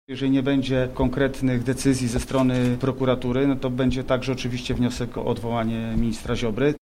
Taką decyzję ogłosił Grzegorz Schetyna na wczorajszej konferencji prasowej w Lublinie.